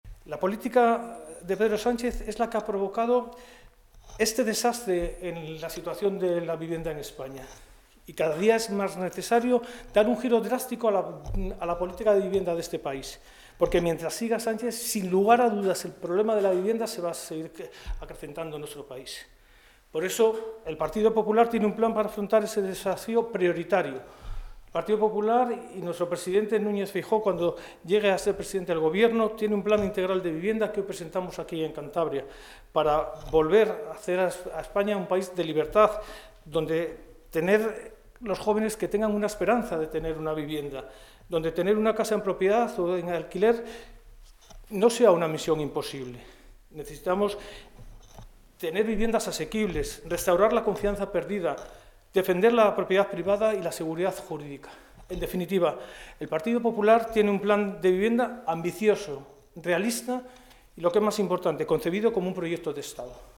El diputado nacional, Félix de las Cuevas, ha presentado hoy en una rueda de prensa el Plan Integral de Vivienda de Feijóo, junto al coordinador del PP cántabro y portavoz parlamentario, Juan José Alonso, quien ha destacado las políticas que está impulsando en esta materia el Gobierno de Buruaga.